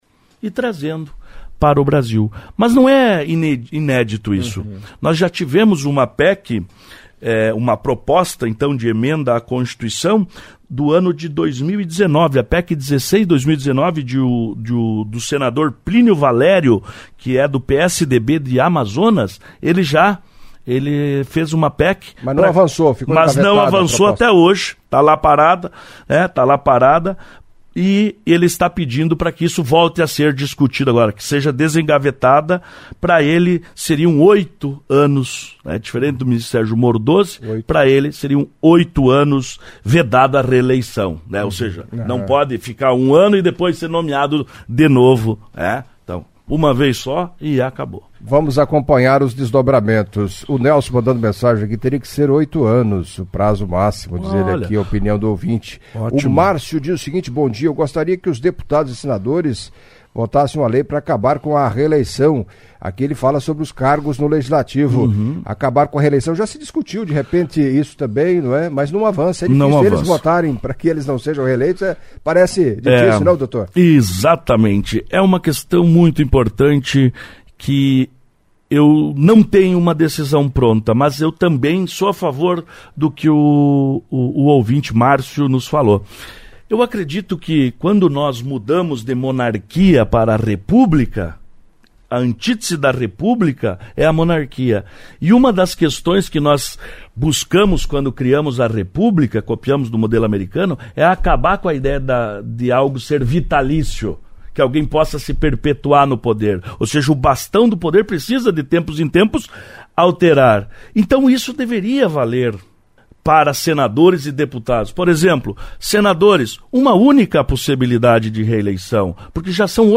Em entrevista à CBN Cascavel nesta quinta-feira (16)